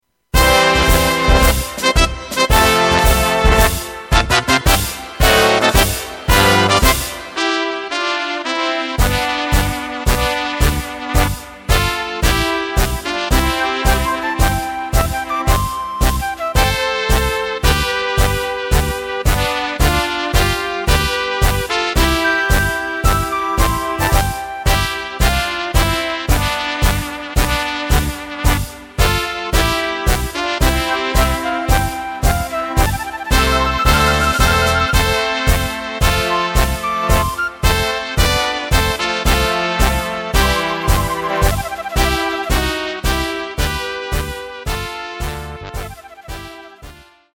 Takt:          2/4
Tempo:         120.00
Tonart:            C
Polka Blasmusik!
Playback mp3 mit Lyrics